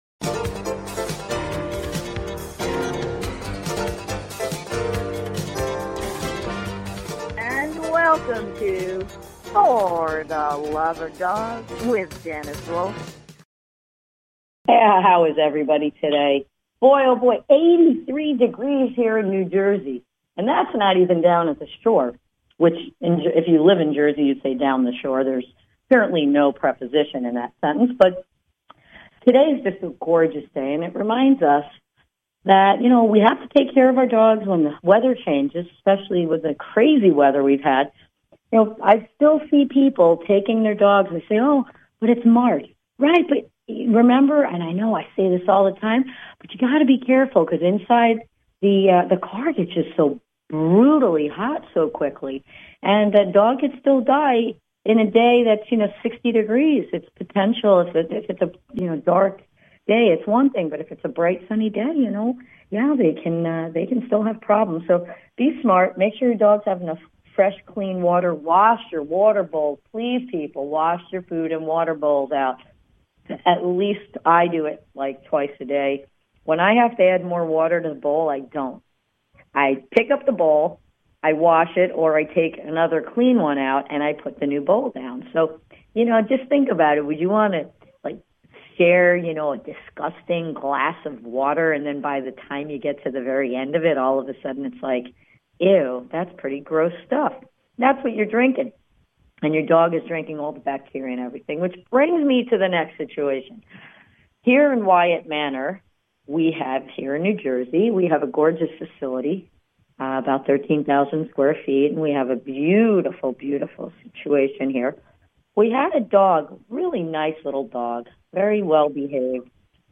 Please consider subscribing to this talk show.